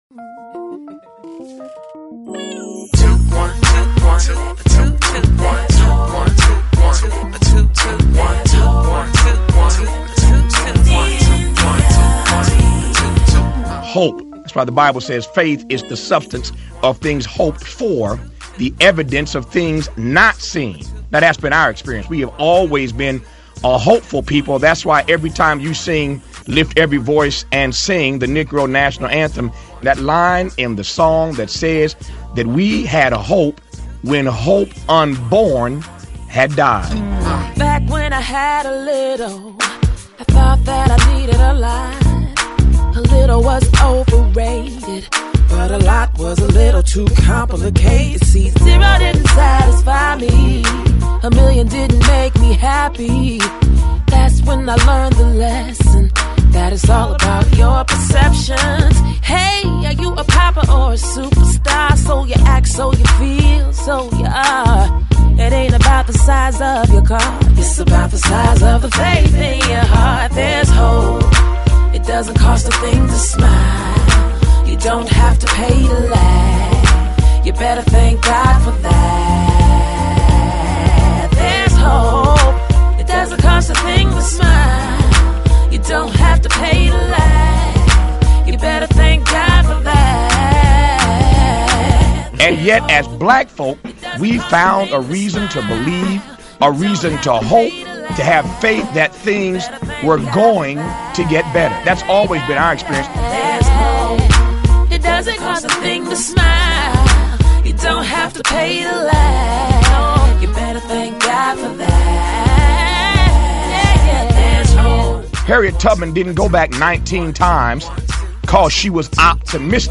One of my jobs on the show is to assemble "mixes" that include elements from the show, sound "bites" from the news and music -- to tell "the story" without just reading cold hard copy, but emotionally, through the use of music and sound.
Occasionally, Tom will ask me to take an especially powerful commentary and mix it to music......